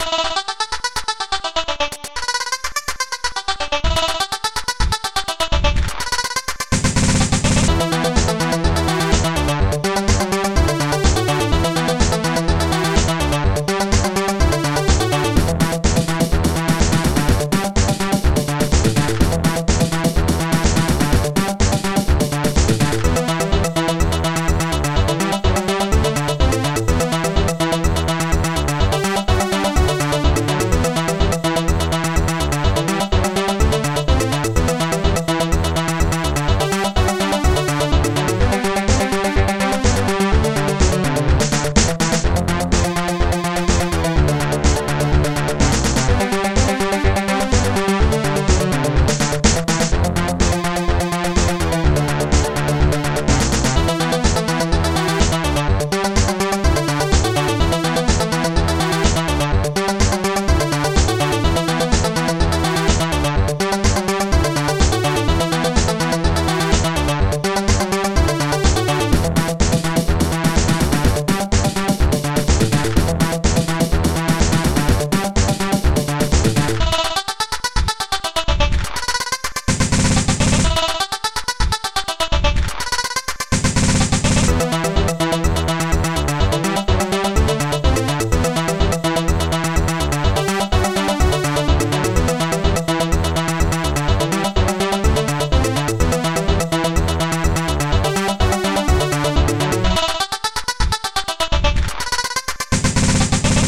synth
st-03:nastybrass
bassdrum3
st-01:closehihat
st-01:strings8